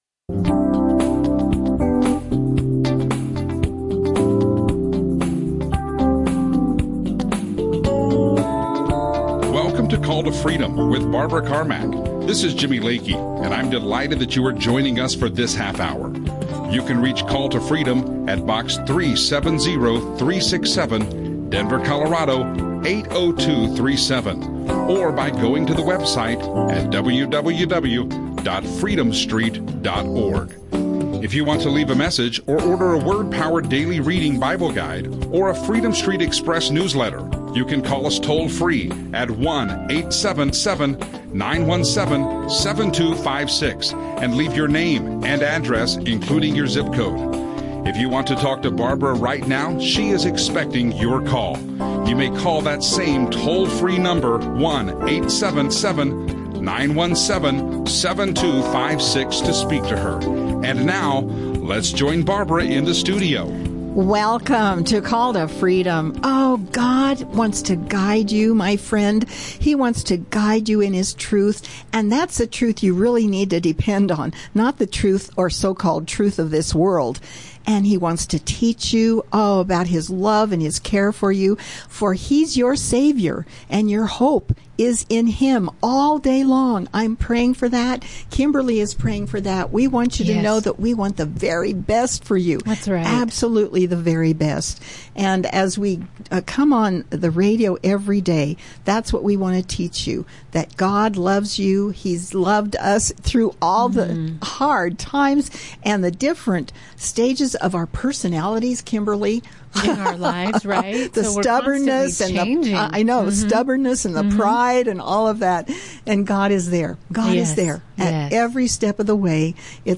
Christian radio
live radio show